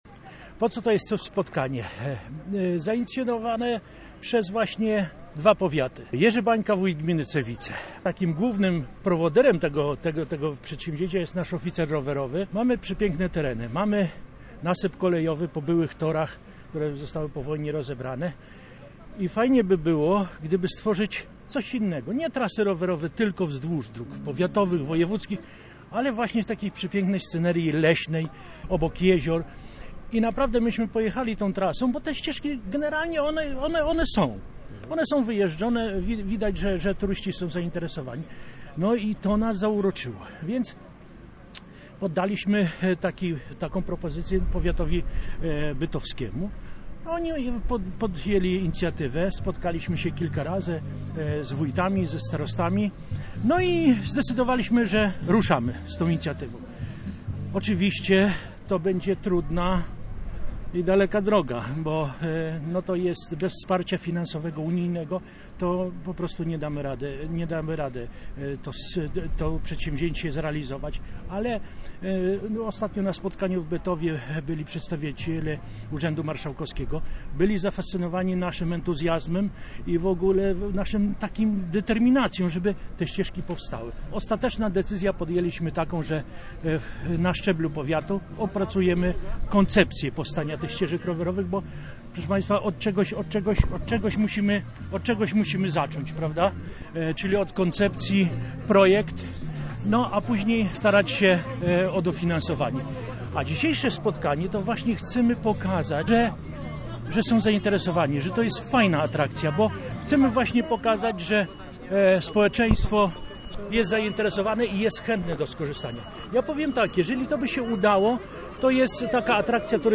Nad jeziorem pod Cewicami zorganizowano piknik promujący powstanie trasy.